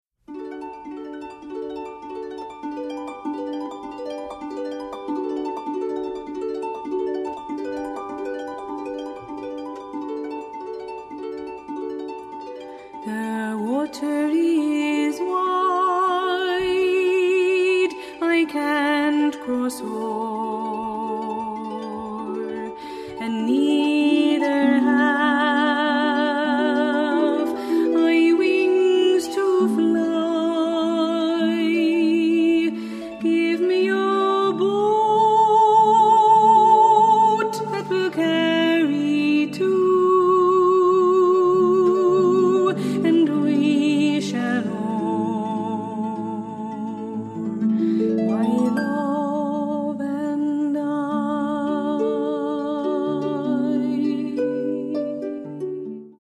Jersey City Irish Harpist 1
This Jersey City Irish Harpist is not only a harpist but also a singer.